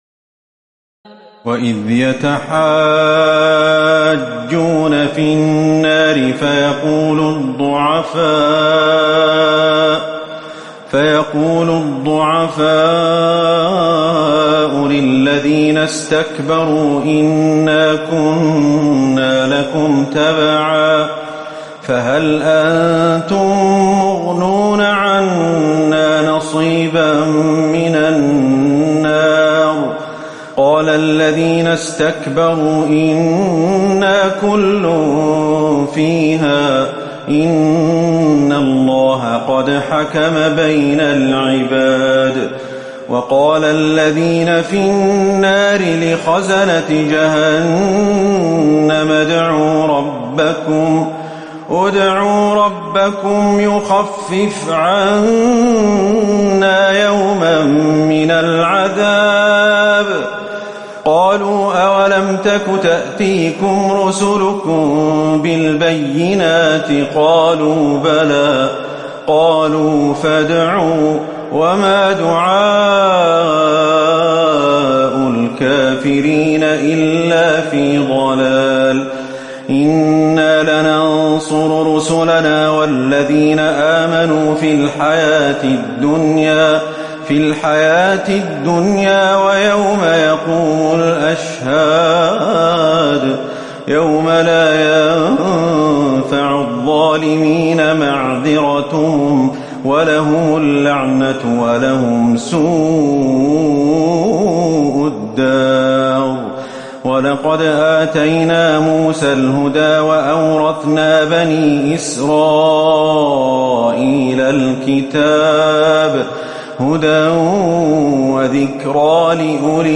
تراويح ليلة 23 رمضان 1438هـ من سور غافر (47-85) وفصلت كاملة Taraweeh 23 st night Ramadan 1438H from Surah Ghaafir and Fussilat > تراويح الحرم النبوي عام 1438 🕌 > التراويح - تلاوات الحرمين